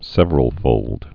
(sĕvər-əl-fōld, sĕvrəl-)